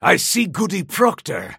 Krill voice line - I see Goody proctor!